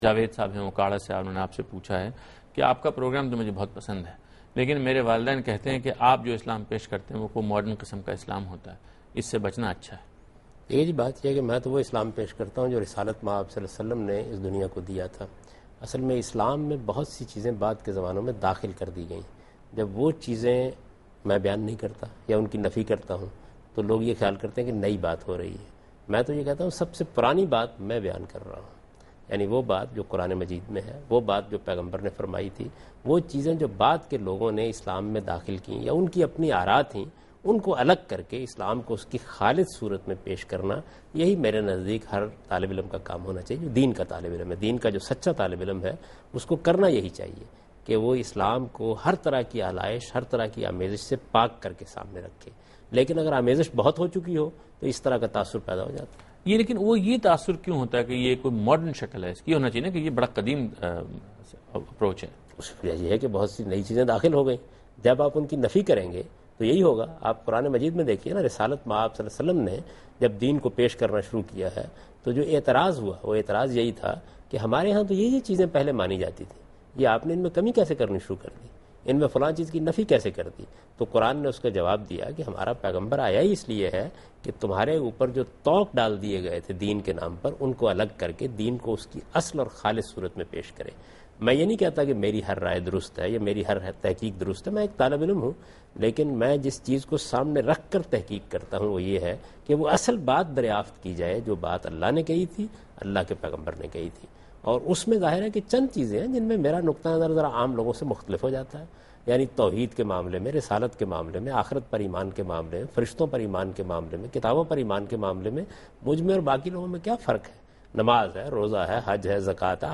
Category: TV Programs / Dunya News / Deen-o-Daanish / Questions_Answers /
دنیا نیوز کے پروگرام دین و دانش میں جاوید احمد غامدی ”کیا غامدی صاحب جدید اسلام پھیلا رہے ہیں؟“ سے متعلق ایک سوال کا جواب دے رہے ہیں